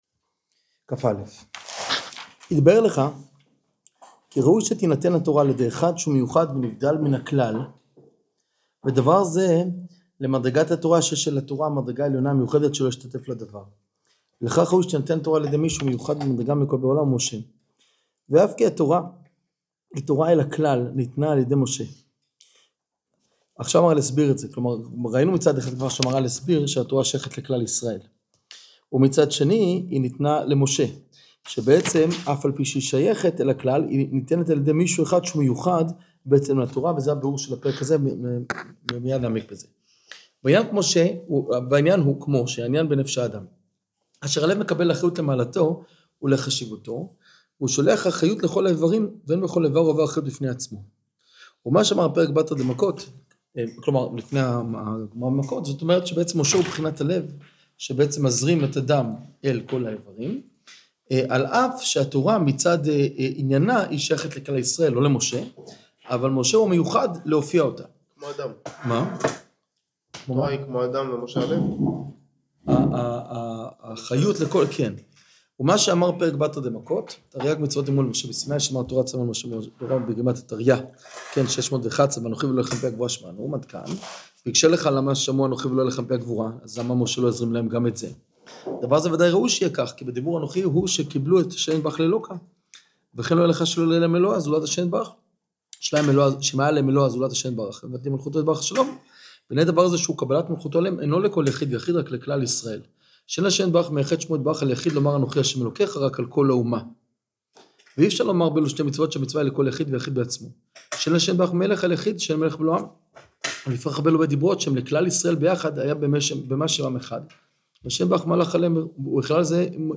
במהלך צפצוף חזק, לא להבהל